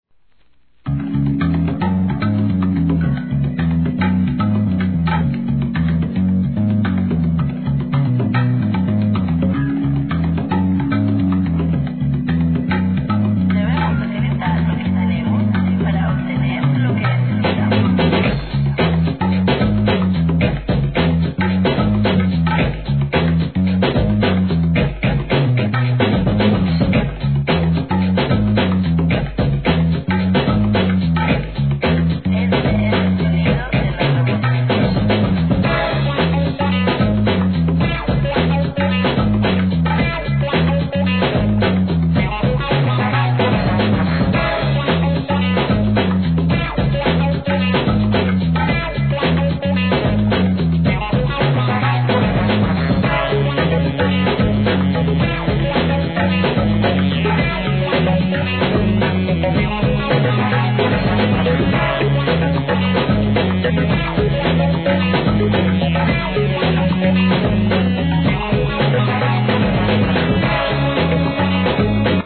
1. HIP HOP/R&B
チャカポコ鳴るBEATにシンセ、ギター、フルートが奏でるアフリカンFUNK!!